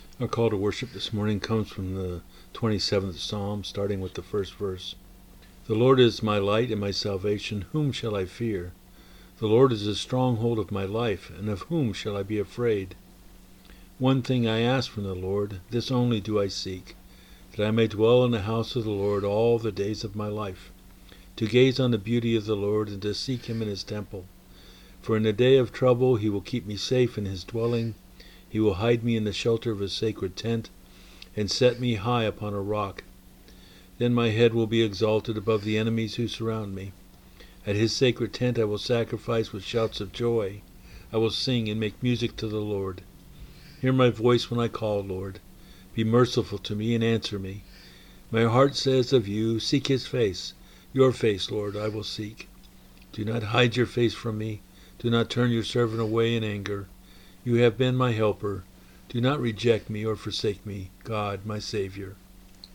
January 22 2023 Service
Call to Worship: